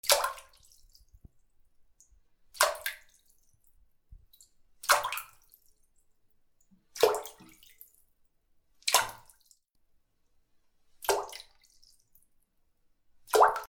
/ M｜他分類 / L30 ｜水音-その他
水に落ちる 水音 小さいものを水に投げ込む
『ポチャン』